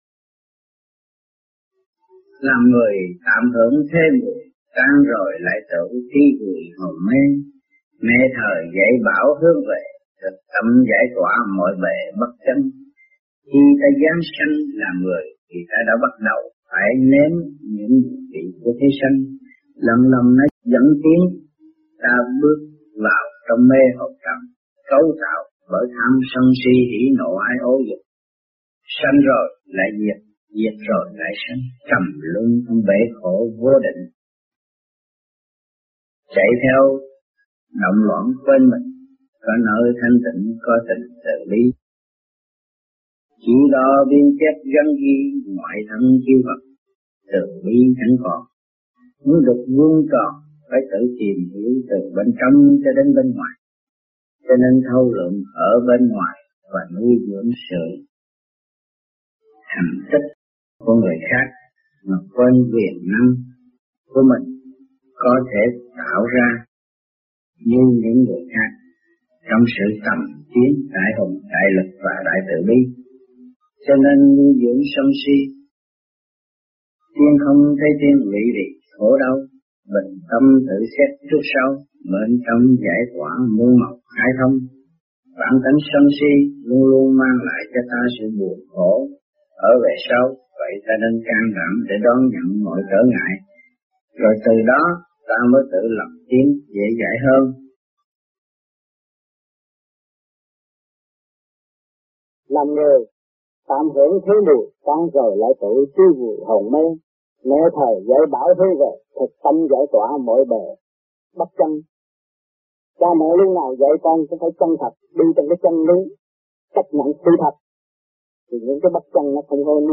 Mẫu Ái Giảng Tại Việt Nam Mẫu Ái - 1972